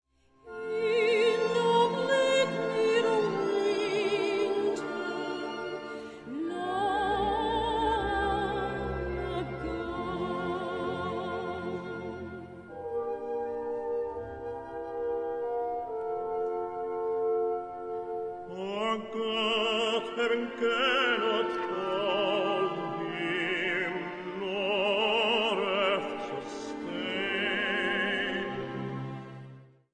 Children Choir
key: F-major